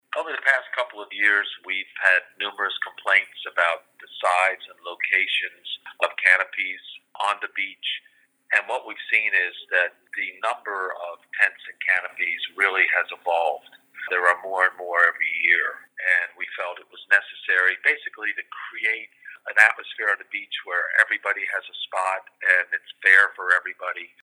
Mayor Rick Meehan tells the talk of Delmarva about complaints that have led up to this…